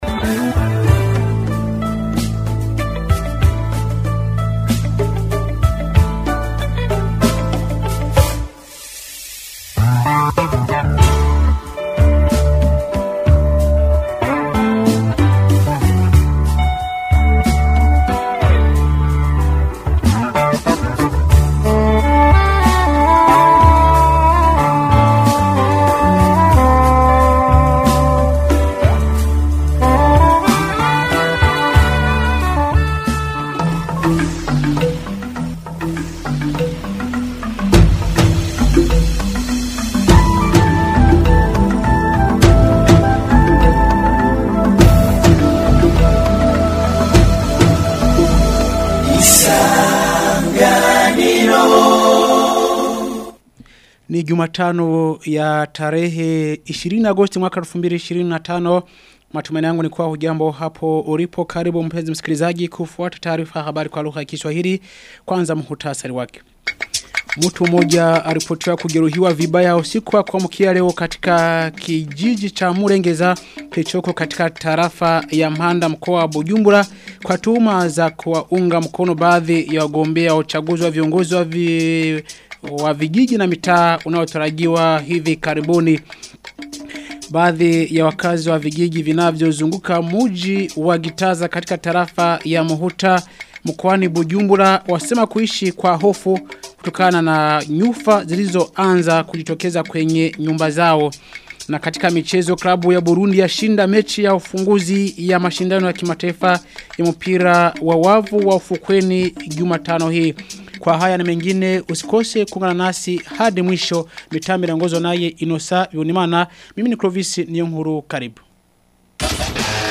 Taarifa ya habari ya tarehe 20 Agosti 2025